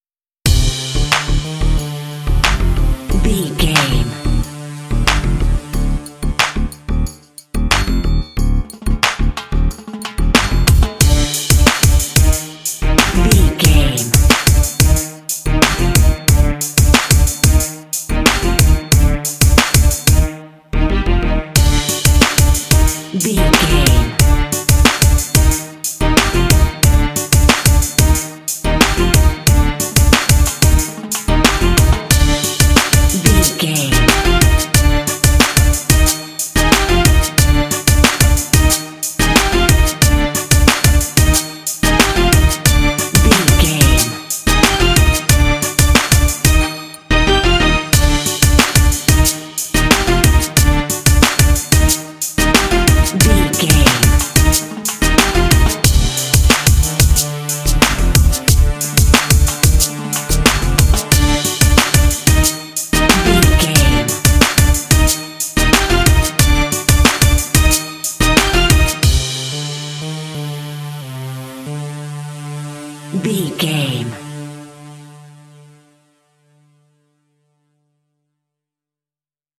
This funky track is great for action and fighting games.
Aeolian/Minor
funky
bouncy
groovy
synthesiser
drums
bass guitar
strings
percussion
Funk